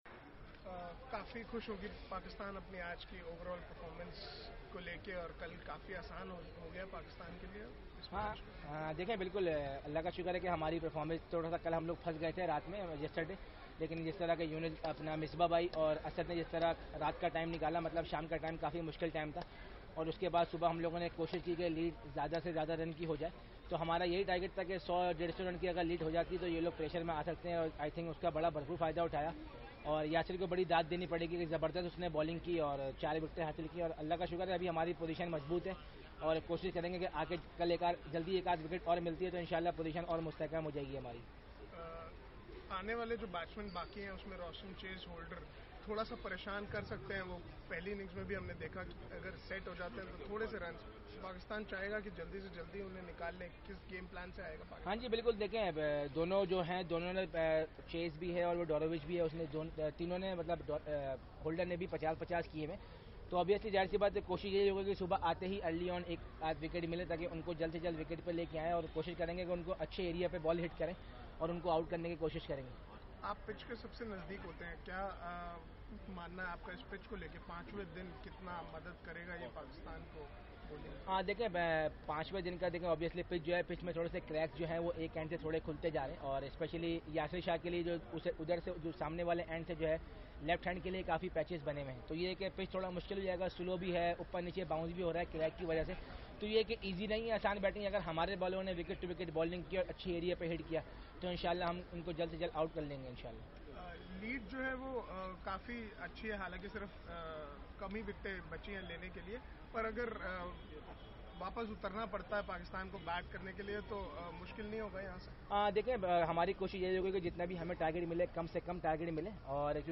Sarfraz Ahmed press conference after day four of first test match in Kingston, Jamaica